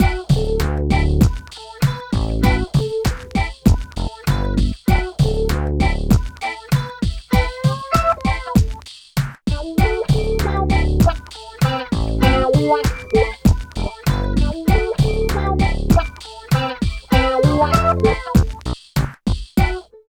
71 LOOP   -L.wav